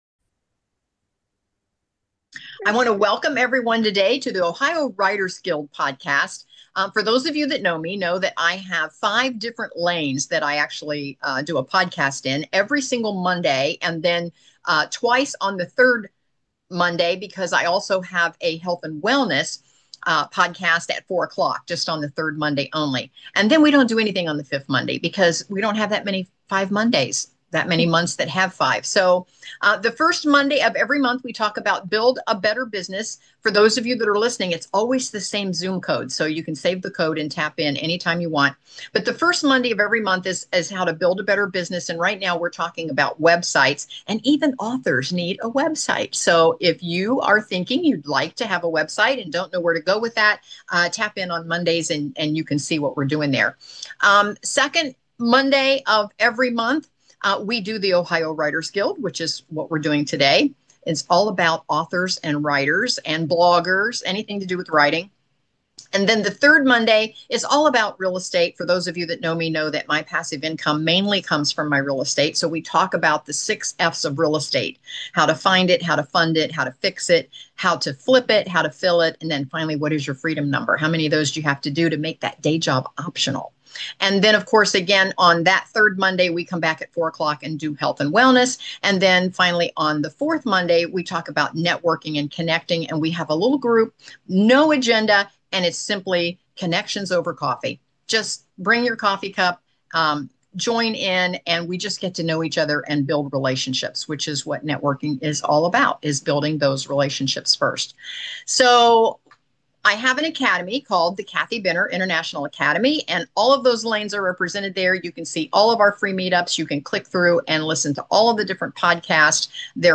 Authors and Writers